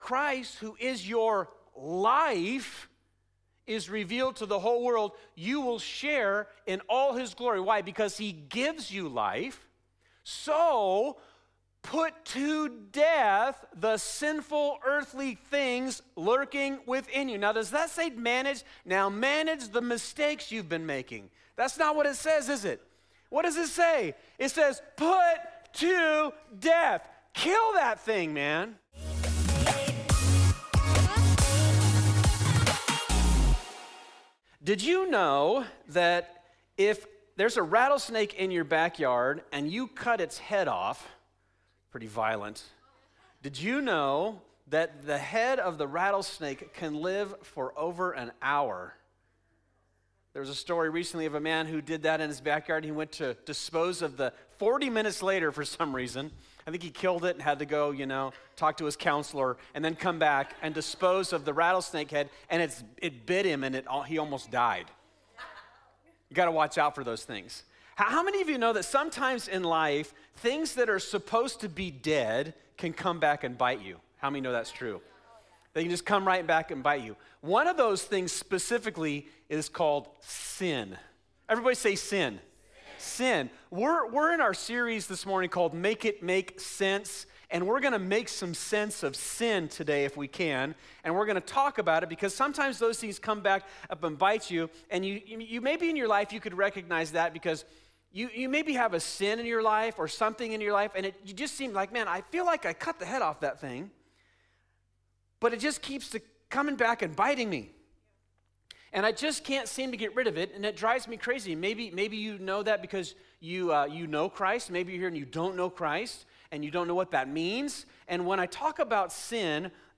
This is part 5 of "Make It Make Sense," our series at Fusion Christian Church where we ask difficult questions and look for biblical answers.